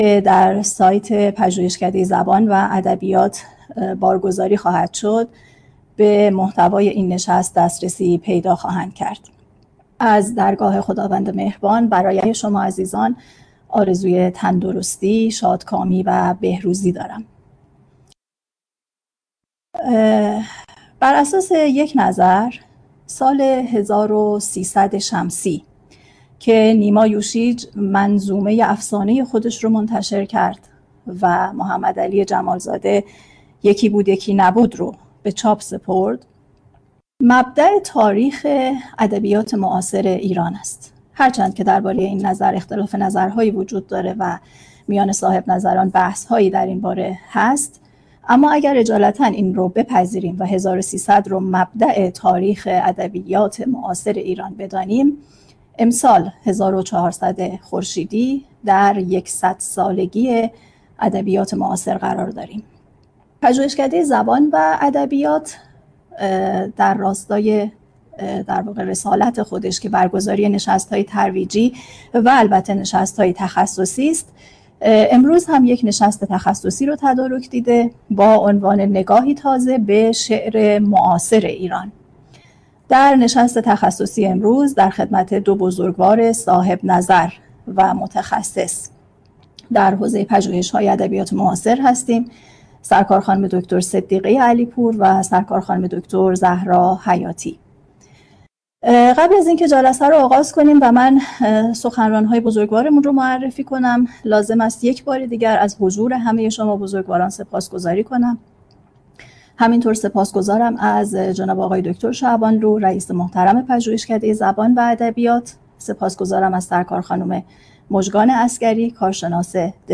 نشست تخصصی نگاهی تازه به شعر معاصر